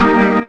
B3 EMAJ 1.wav